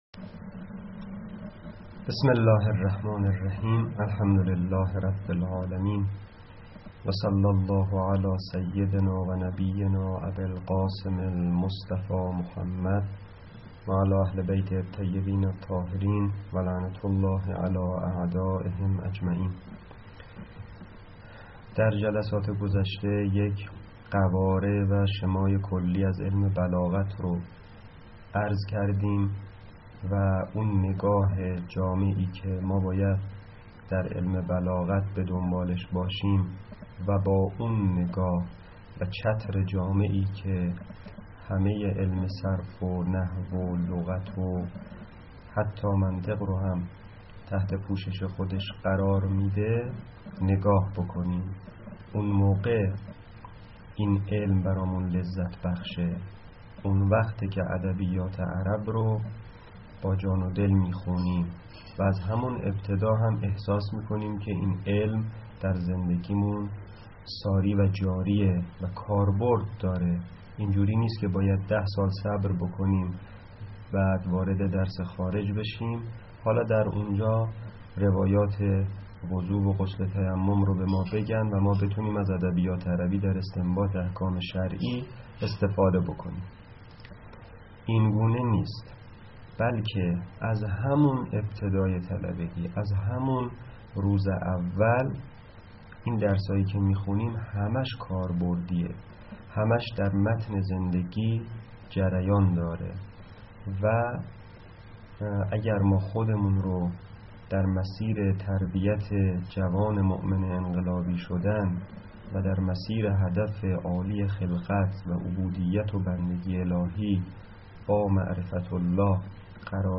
.جلسه سوم تدریس بلاغت